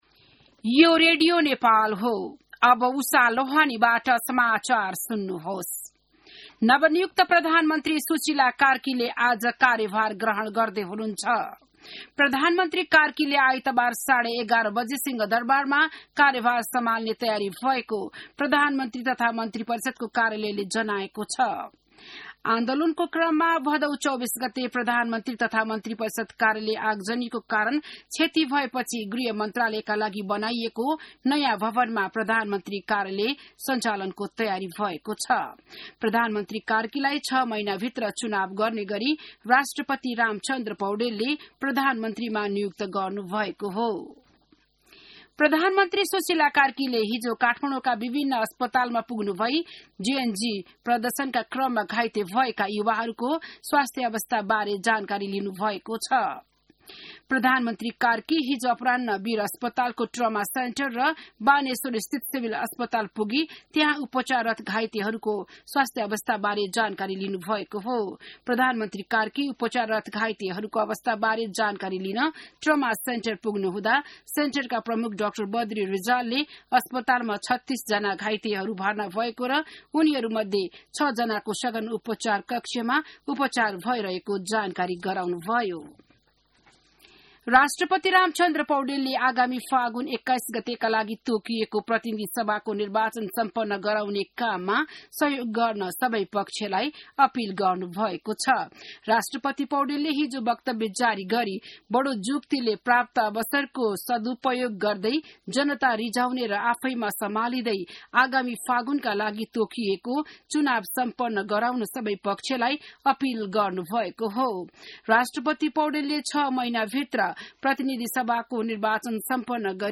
बिहान १० बजेको नेपाली समाचार : २९ भदौ , २०८२